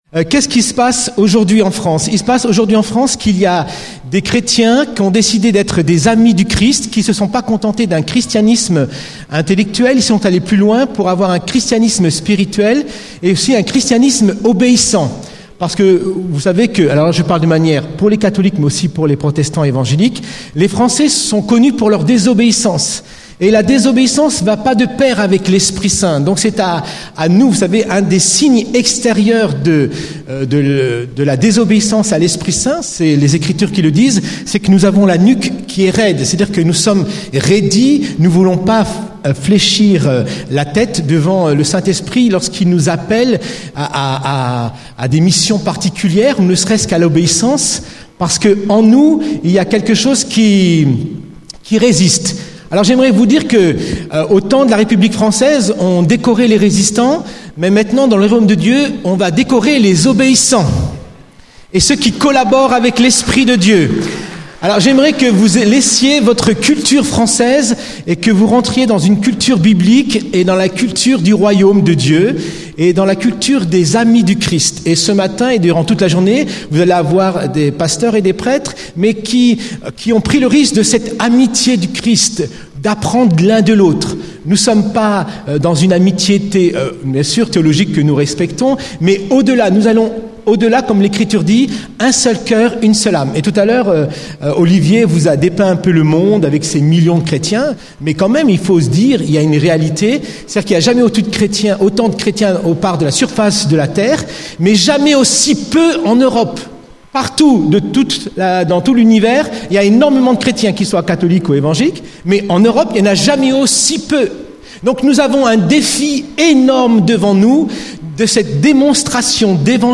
Enregistr� lors du rassemblement "Recevez l'Esprit Saint" organis� par "Promesses de Dieu" le 15 novembre 2008 � Paris avec le P�re Cantalamessa